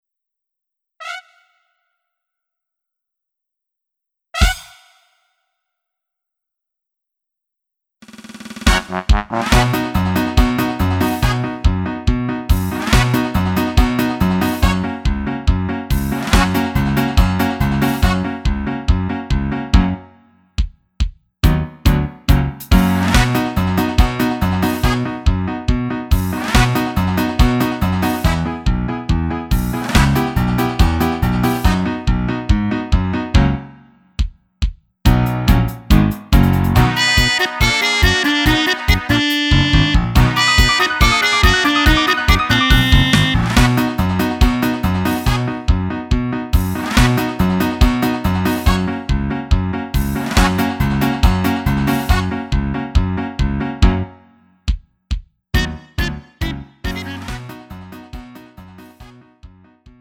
음정 원키 3:56
장르 구분 Lite MR